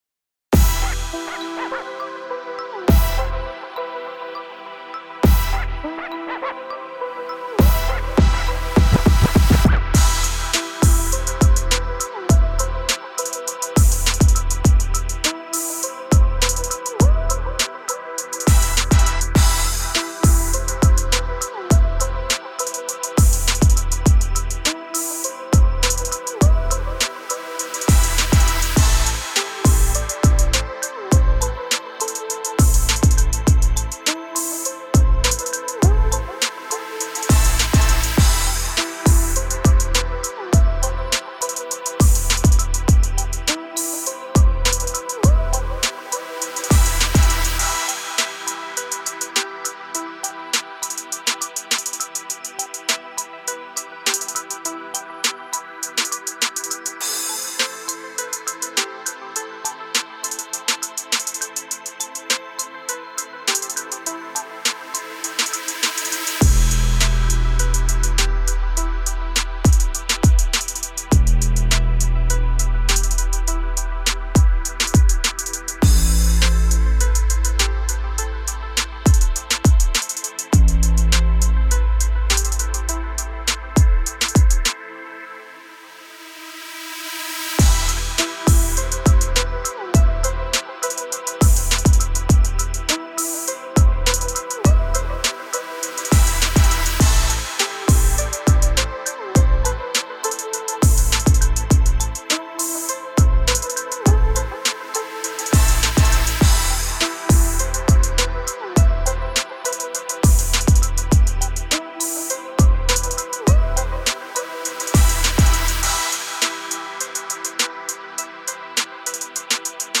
هیپ‌هاپ
موسیقی بی کلام هیپ هاپ